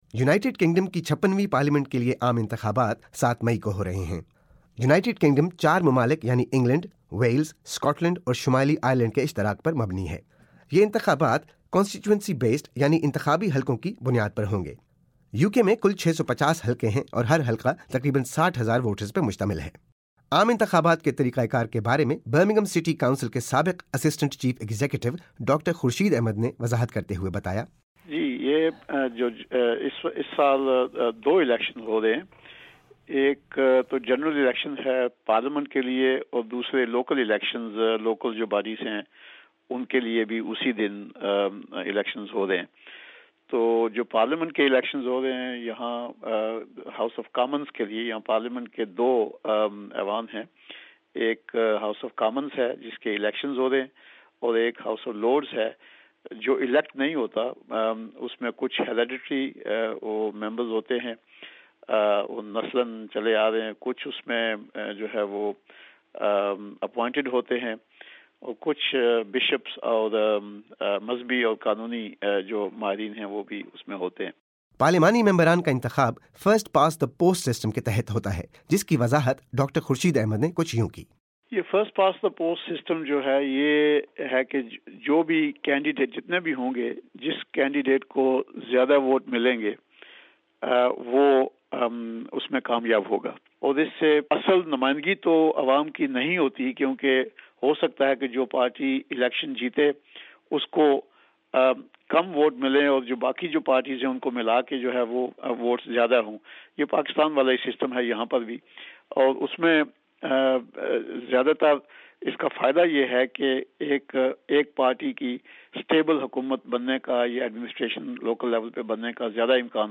برطانیہ میں7 مئی کو ہونے والے عام انتخابات پر رپورٹ